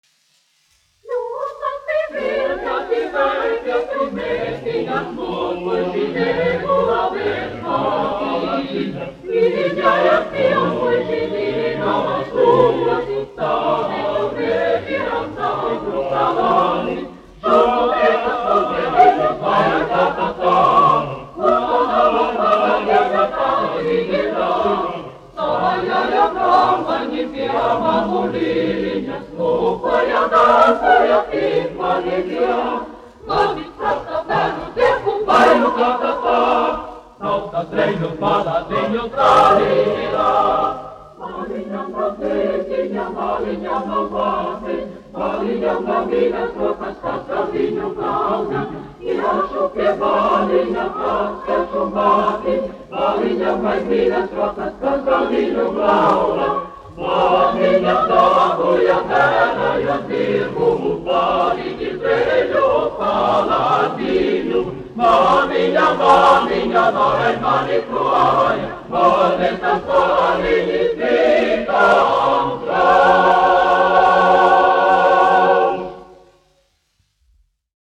Latvijas Radio koris, izpildītājs
Kalniņš, Teodors, 1890-1962, diriģents
1 skpl. : analogs, 78 apgr/min, mono ; 25 cm
Latviešu tautasdziesmas
Kori (jauktie)
Latvijas vēsturiskie šellaka skaņuplašu ieraksti (Kolekcija)